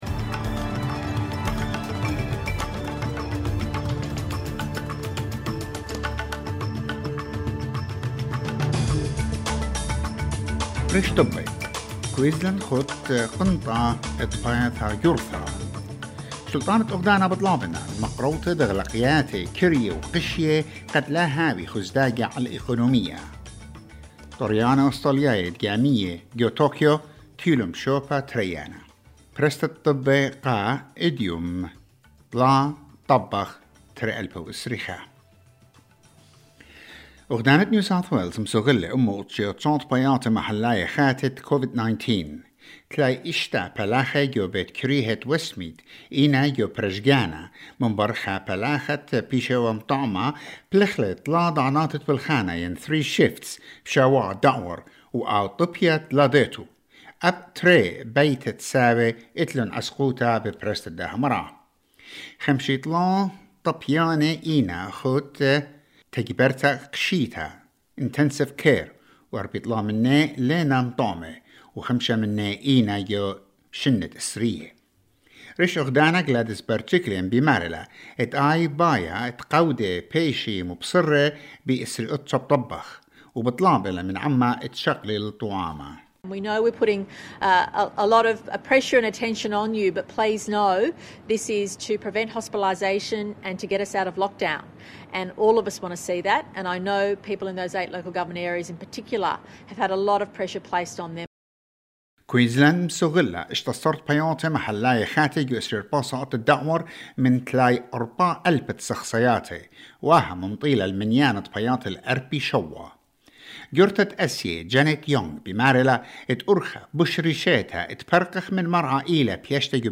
SBS NEWS IN ASSYRIAN 3 AUGUST 2021